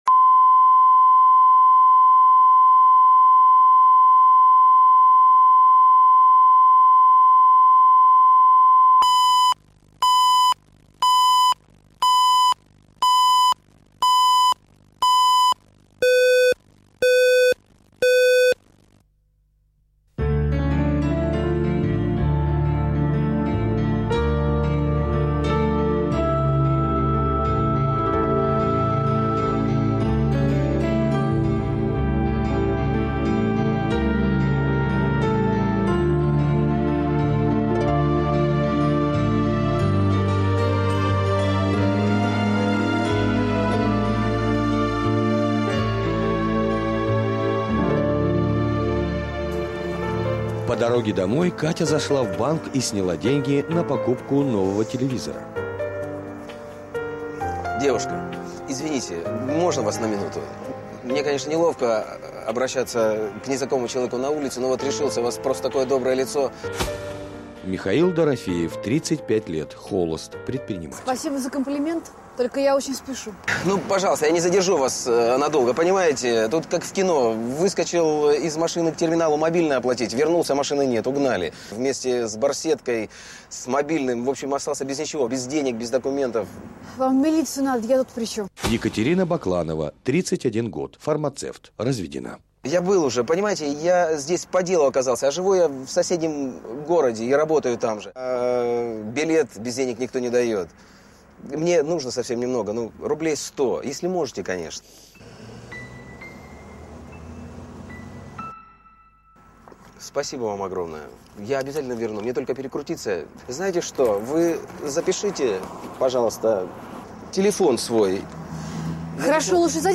Аудиокнига Все и сразу | Библиотека аудиокниг